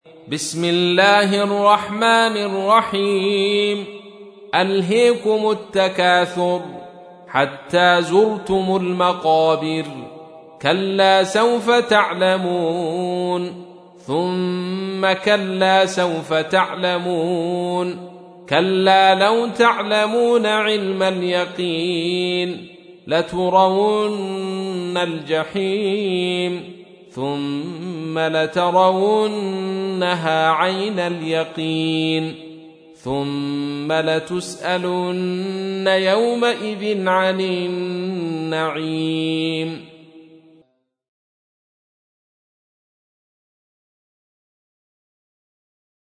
102. سورة التكاثر / القارئ